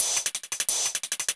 Index of /musicradar/ultimate-hihat-samples/175bpm
UHH_ElectroHatC_175-05.wav